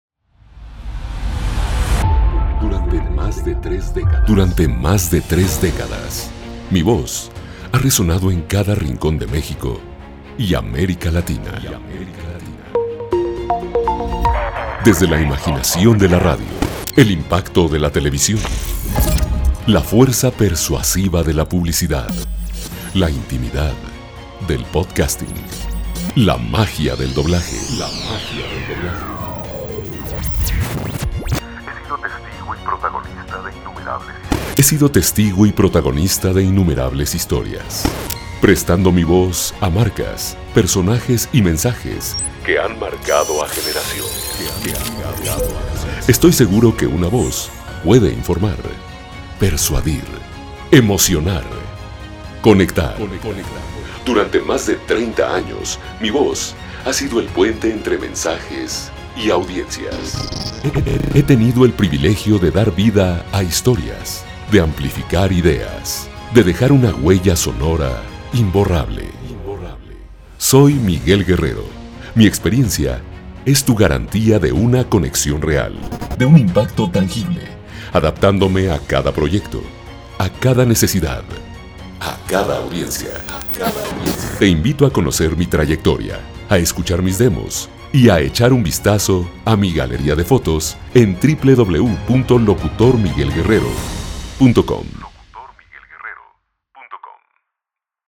Escuchar este demo reel (MP3)
Demo_Locutor_2025.mp3